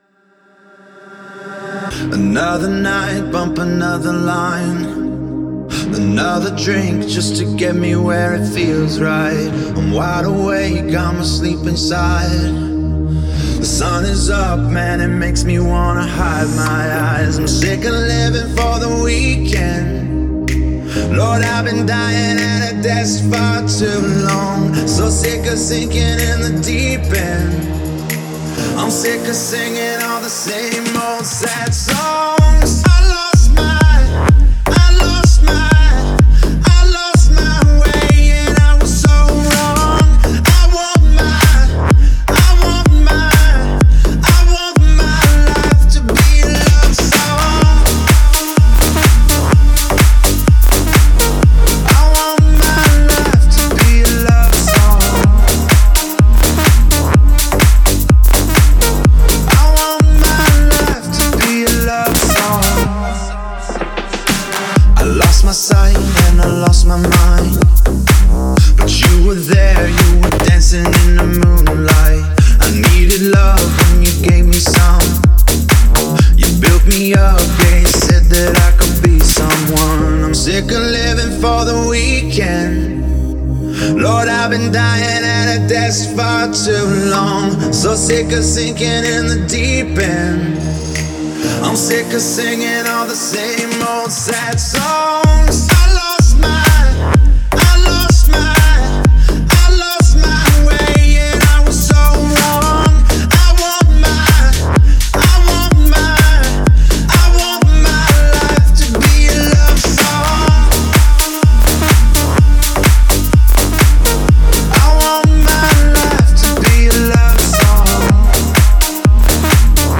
это энергичная трек в жанре хаус